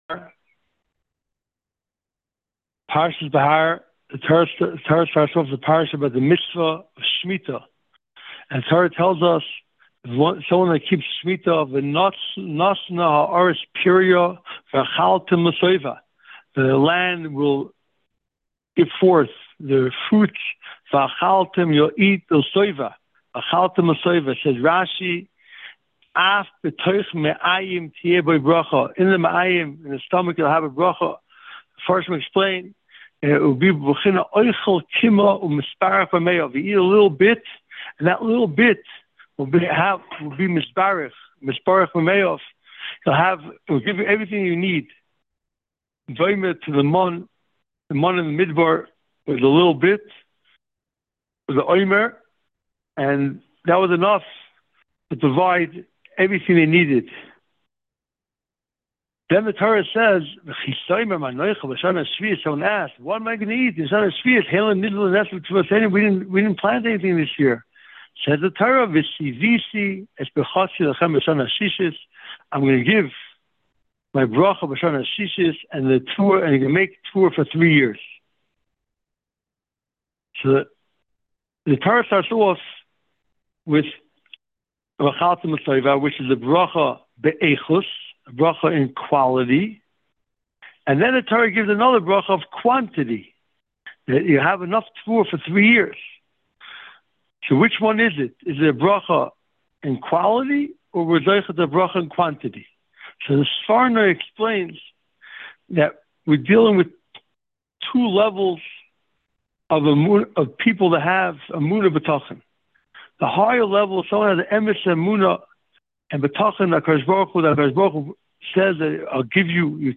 Parsha Preview Audio